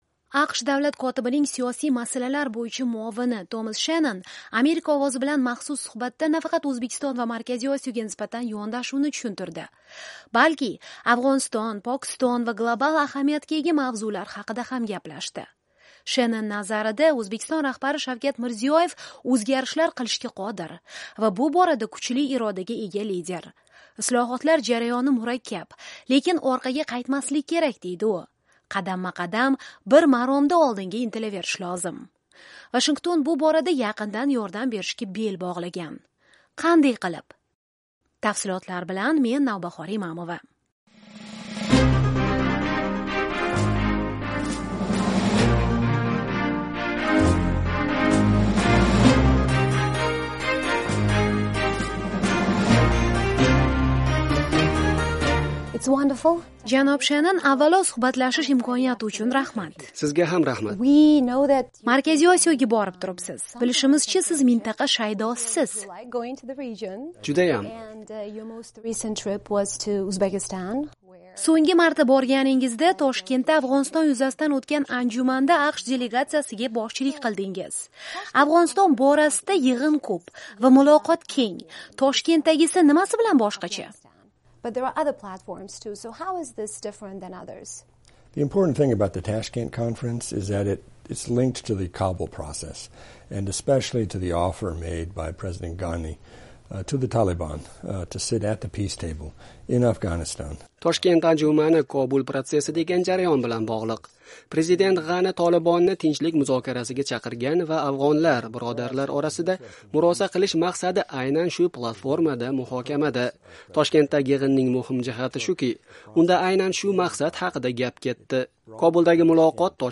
AQSh-Markaziy Osiyo: Davlat katibi muavini bilan suhbat
AQSh Davlat kotibining Siyosiy masalalar bo'yicha muovini Tomas Shennon "Amerika Ovozi" bilan maxsus suhbatda nafaqat O'zbekiston va Markaziy Osiyoga nisbatan yondashuvni tushuntirdi, balki Afg'oniston, Pokiston va global ahamiyatga ega mavzular haqida ham gaplashdi.